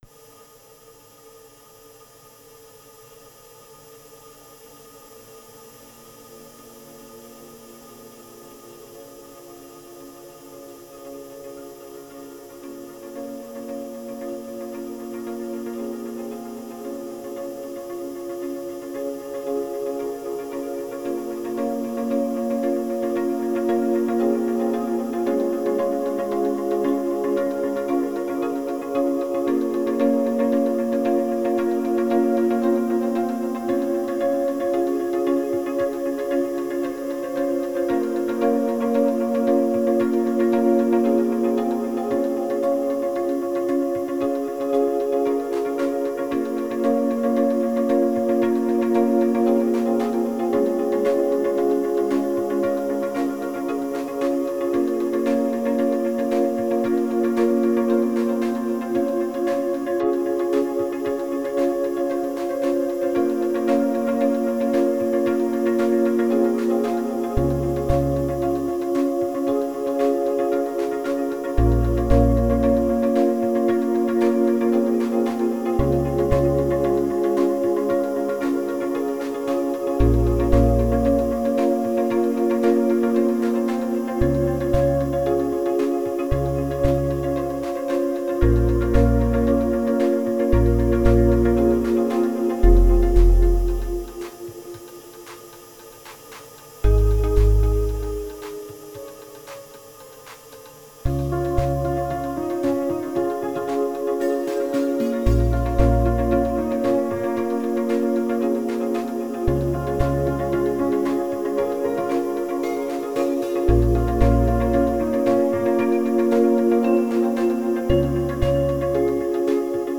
Tags: Piano, Percussion, Digital
Just a jaunty thing